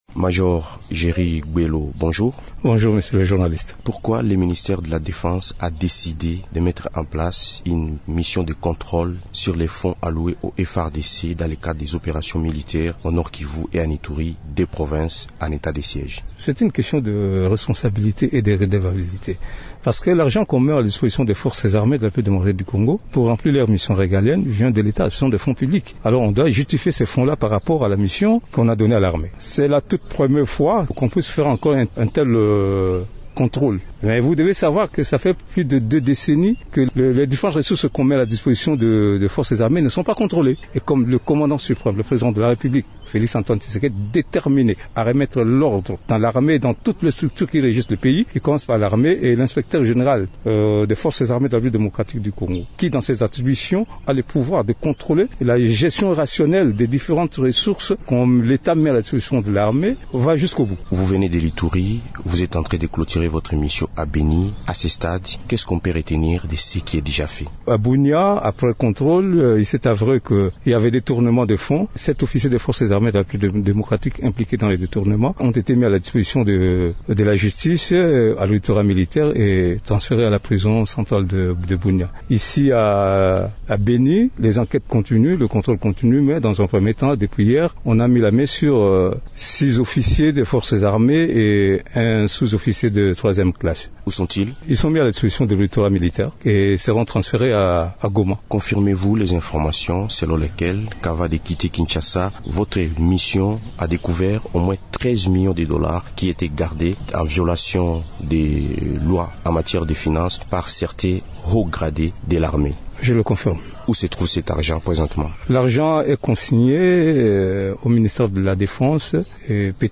Invité de Radio Okapi